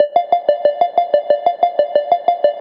FK092SYNT1-L.wav